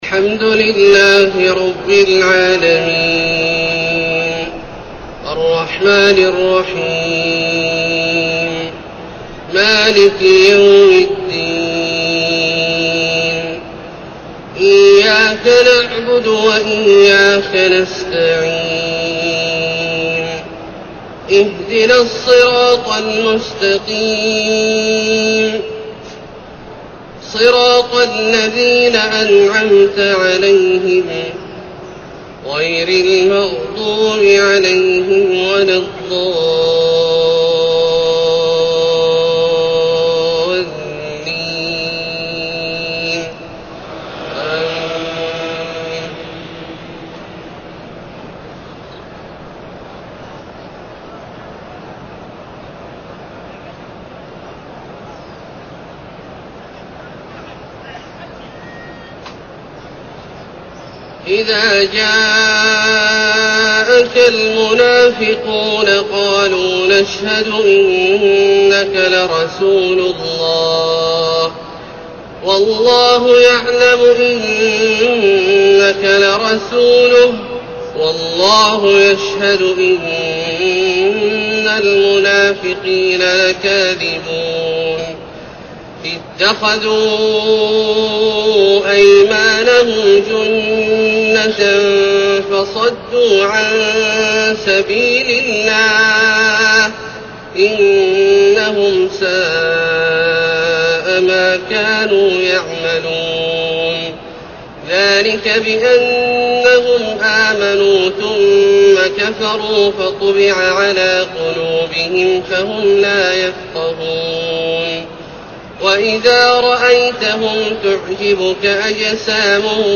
فجر 1-2-1432هـ سورة المنافقون > ١٤٣٢ هـ > الفروض - تلاوات عبدالله الجهني